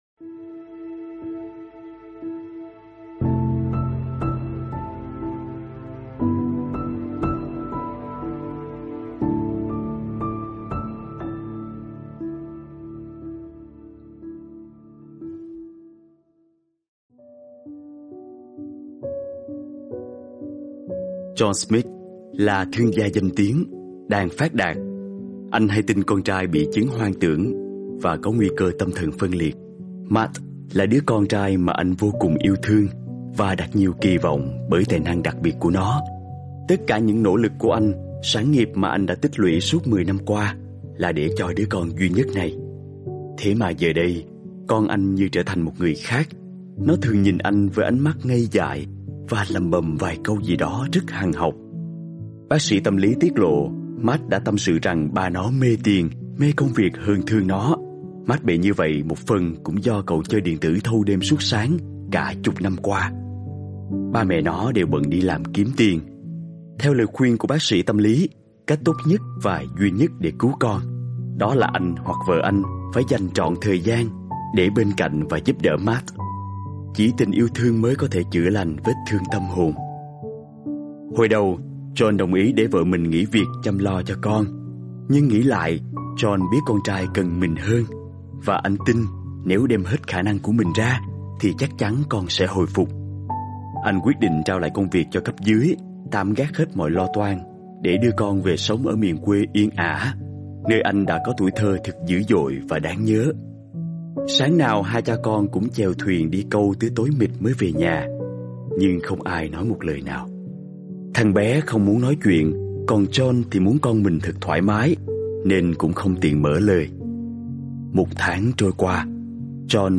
Sách nói mp3 Thuyết pháp